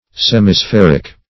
Search Result for " semispheric" : The Collaborative International Dictionary of English v.0.48: Semispheric \Sem`i*spher"ic\, Semispherical \Sem`i*spher"ic*al\, a. Having the figure of a half sphere.